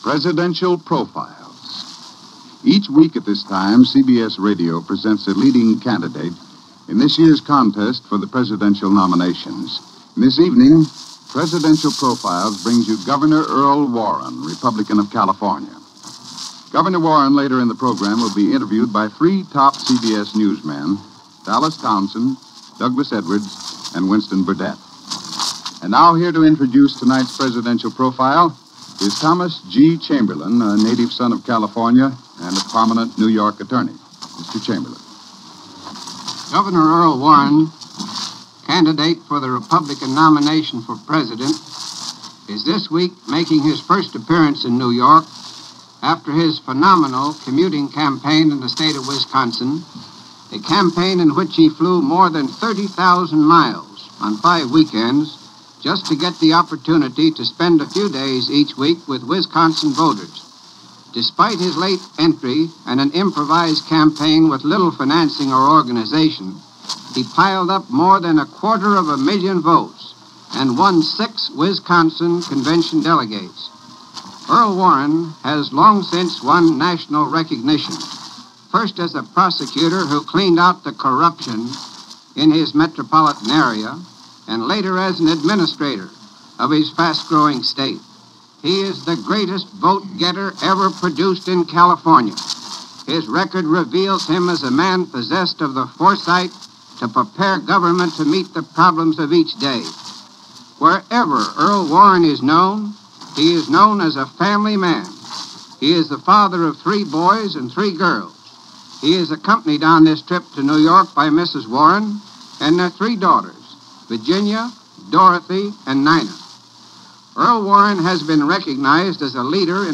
Earl Warren - Candidate For President - 1952 - Past Daily Reference Room - broadcast on April 10, 1952 - CBS Radio - Presidential Profiles.